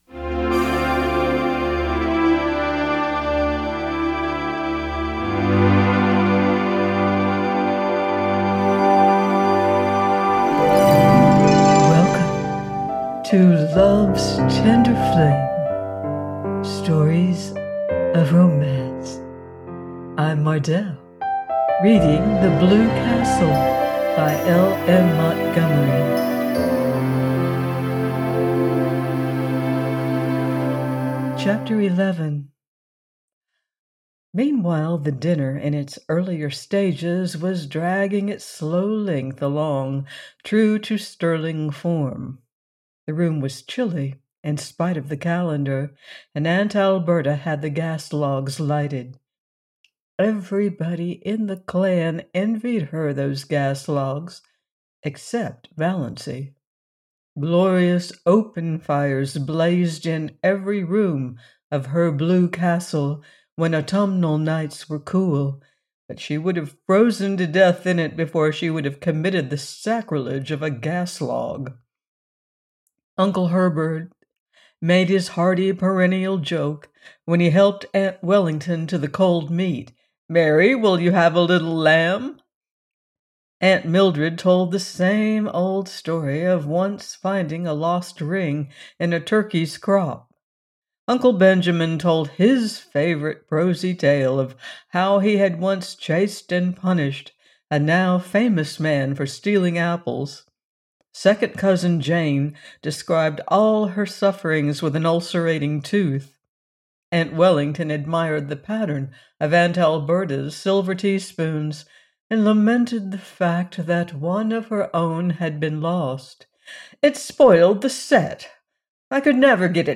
The Blue Castle by L.M. Montgomery - audiobook